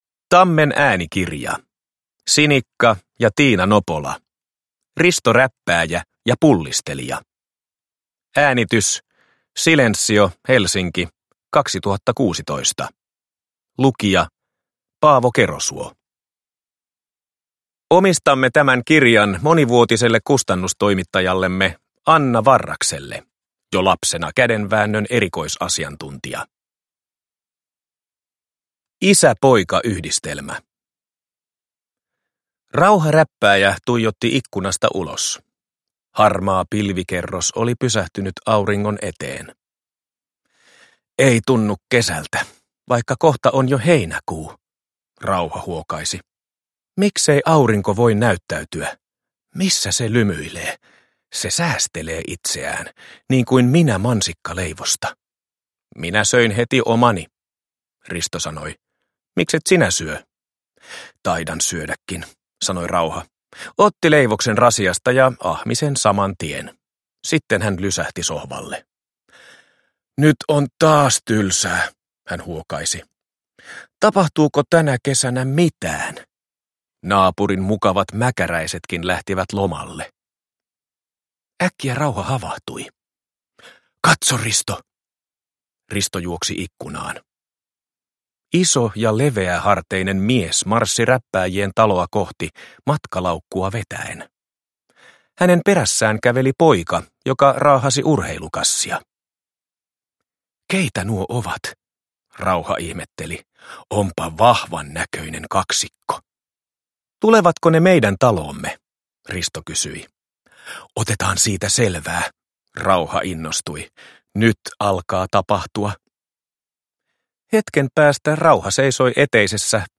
Risto Räppääjä ja pullistelija – Ljudbok